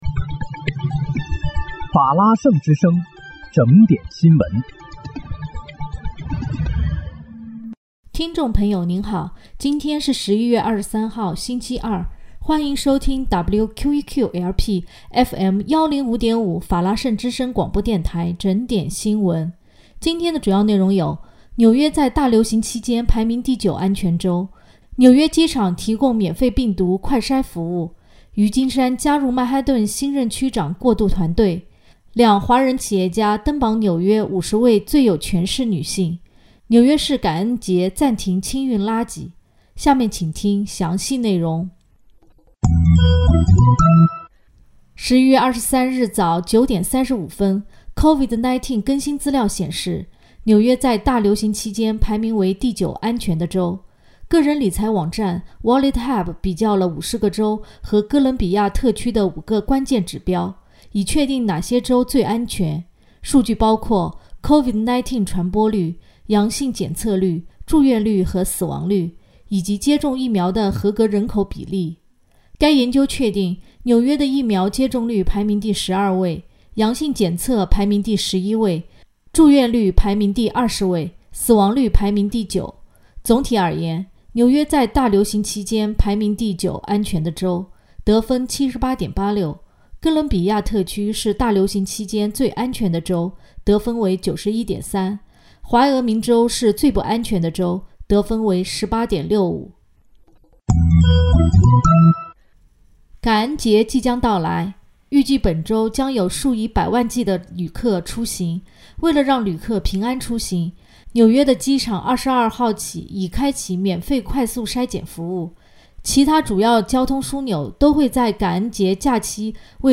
11月23日（星期二）纽约整点新闻